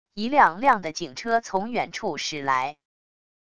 一辆辆的警车从远处驶来wav音频